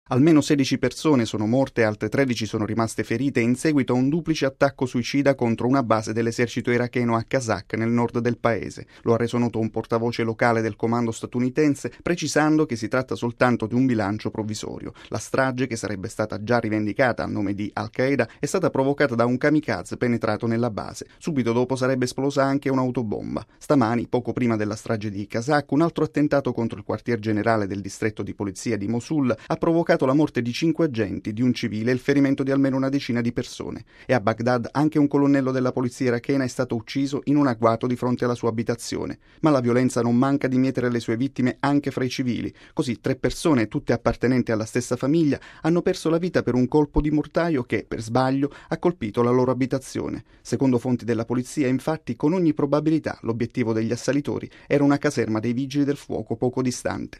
(26 giugno 2005 - RV) In Iraq forze di polizia ancora nel mirino dei ribelli. Diversi attentati kamikaze hanno provocato almeno una trentina di vittime, la maggior parte delle quali sarebbero civili iracheni che lavoravano per le forze armate governative. Il servizio